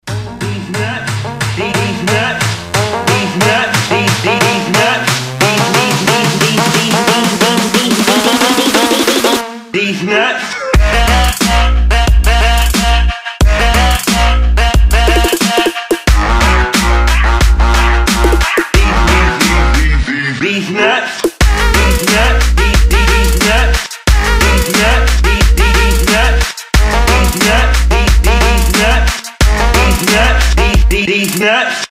• Качество: 273, Stereo
веселые
Dubstep
качает